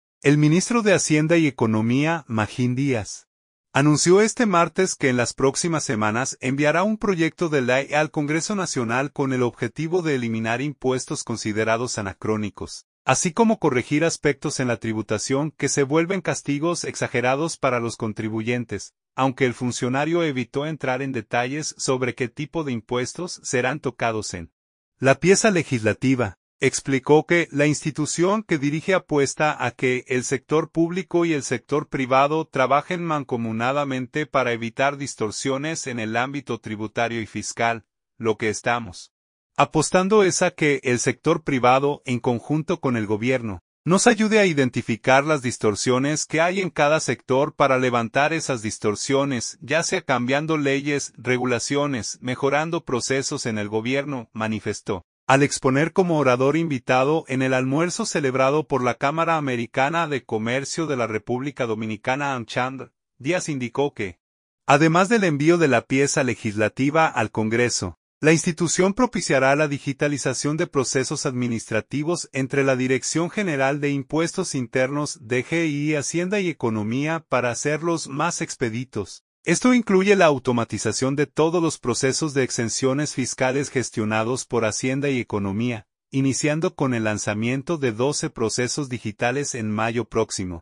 Al exponer como orador invitado en el almuerzo celebrado por la Cámara Americana de Comercio de la República Dominicana (Amchamdr), Díaz indicó que, además del envío de la pieza legislativa al Congreso, la institución propiciará la digitalización de procesos administrativos entre la Dirección General de Impuestos Internos (DGII) y Hacienda y Economía, para hacerlos "más expeditos".